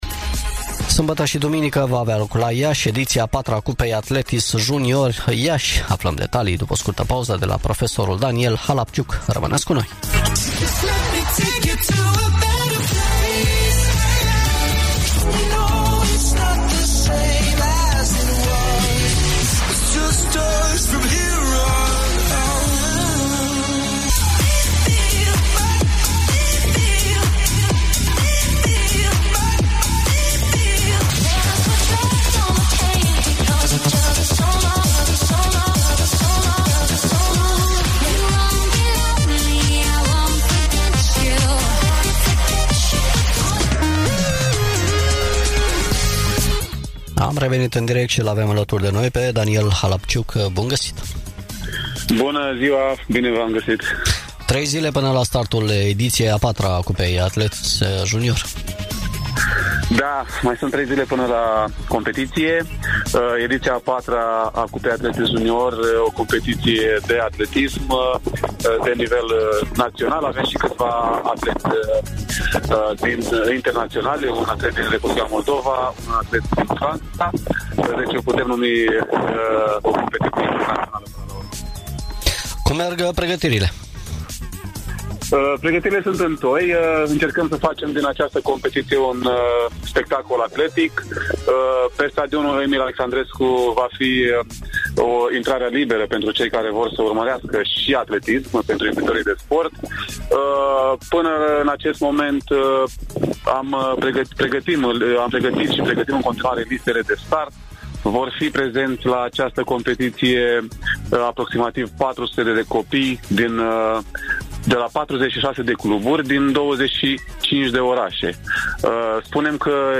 Interviu-12-iunie.mp3